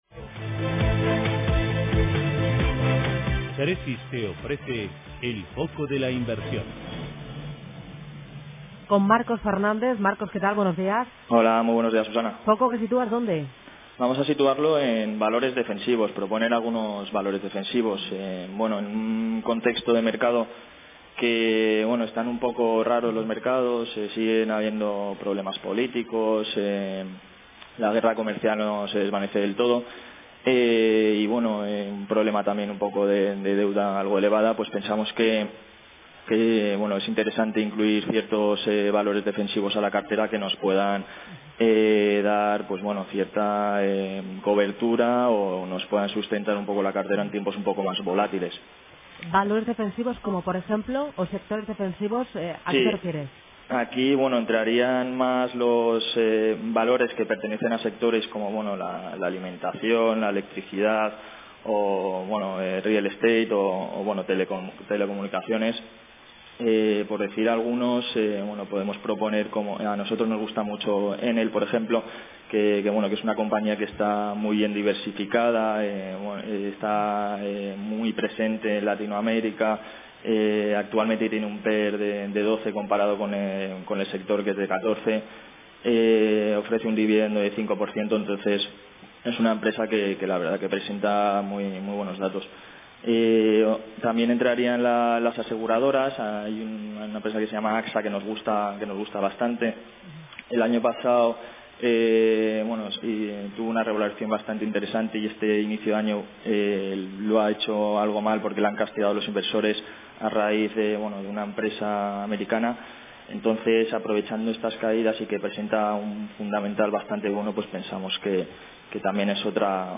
En la radio